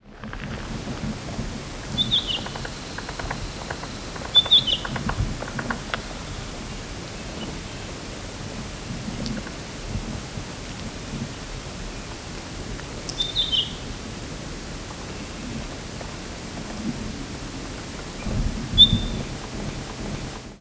Pine Grosbeak diurnal flight calls
"Whu-whee" and "whit-whu-whee" calls from two perched birds with Red Squirrel and Gray Jay calling in the background.